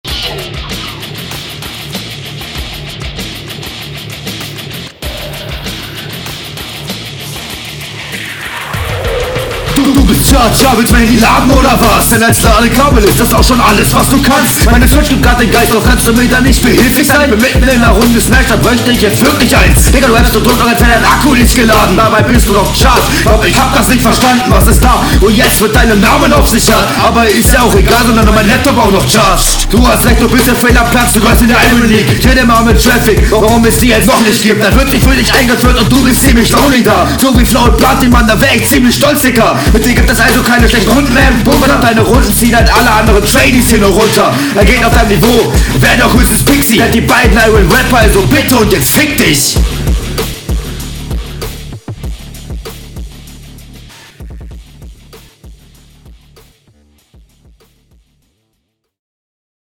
Flow: Teilweise zu gehetzt Text: Akku nicht geladen Line lustig Soundqualität: Man versteht nicht viel, …
Flow: Flow öfters undeutlich und teils offbeat.
Flow: Yeah, also flow ist ziemlich holprig. Bei solchen trap metalbeats ist manchmal weniger mehr …